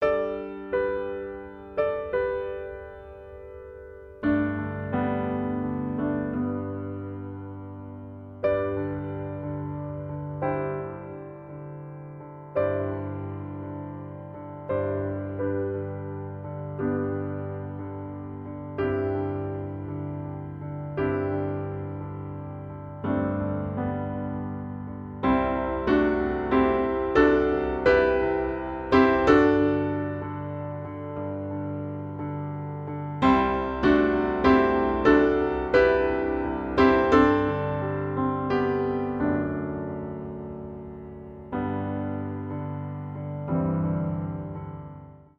Vocal Duet or SA Choir with Violin
Listen to vocal solo in Bb